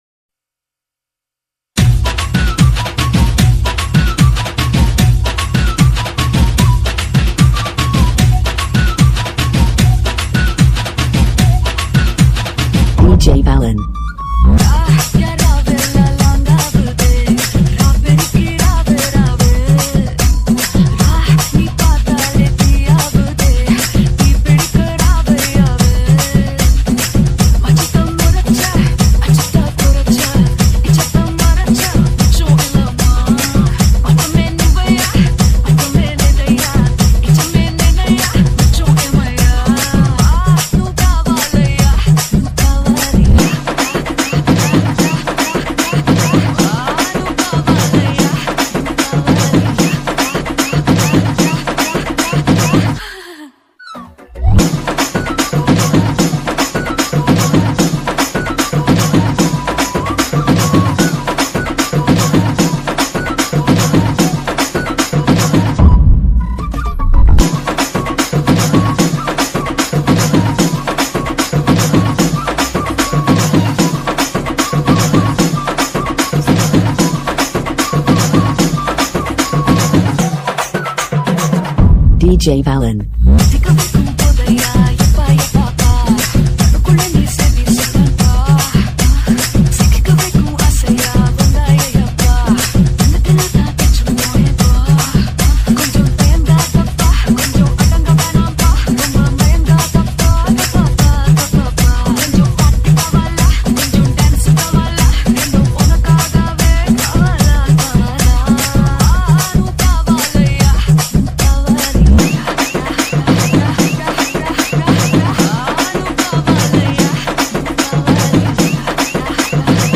TAMIL ITEM DJ REMIX SONG